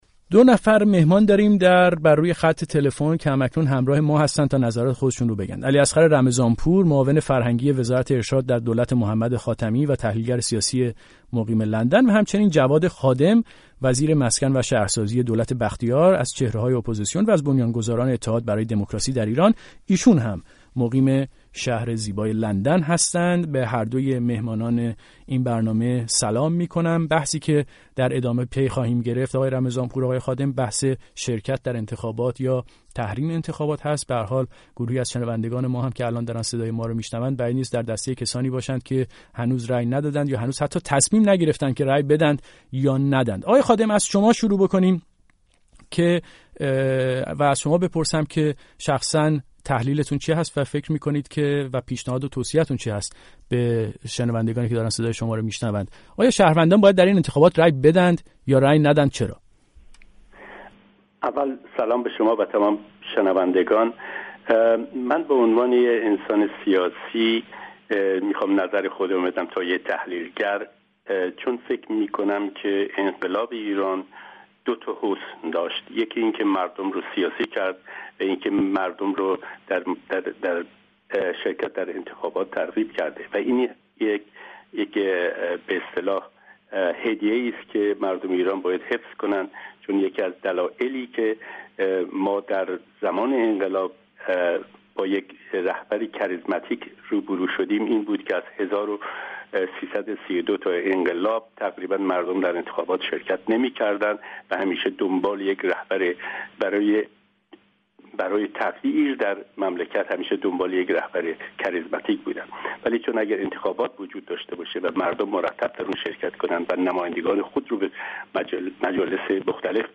میزگرد انتخاباتی رادیو فردا با حضور جواد خادم و علی اصغر رمضانپور